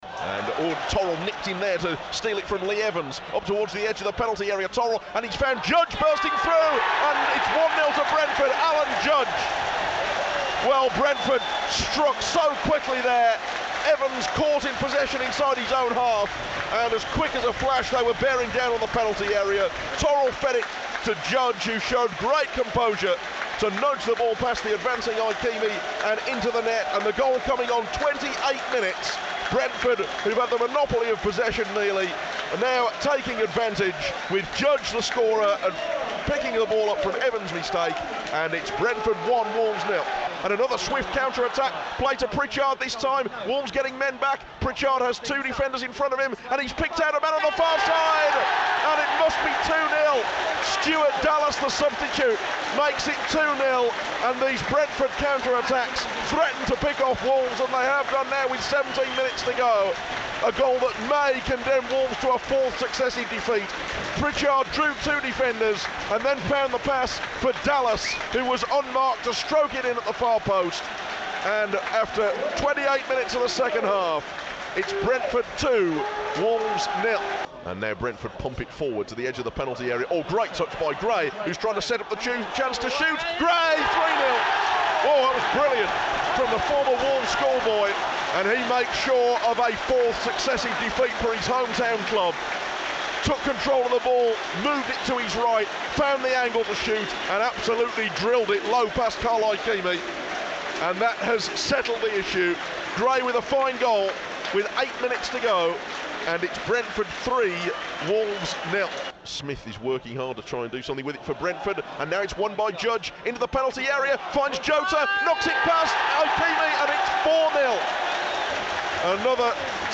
describes the action and talks to Kenny Jackett.